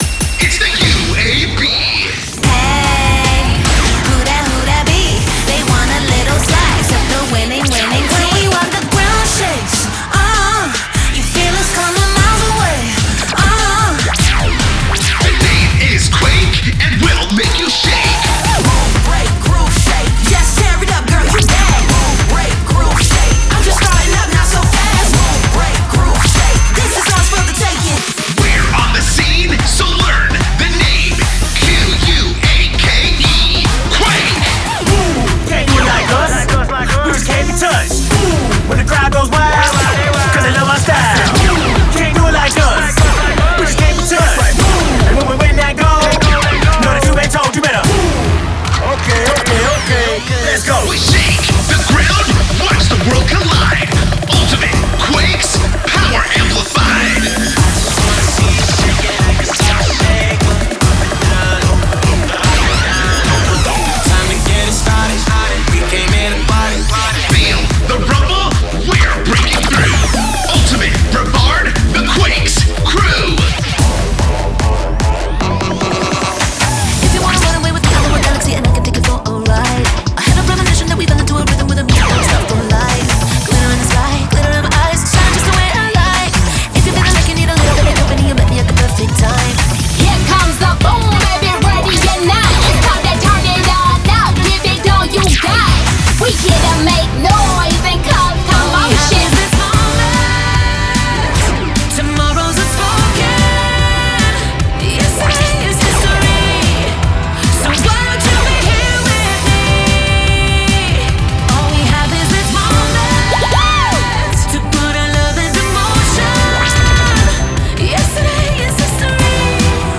# Vocalists -2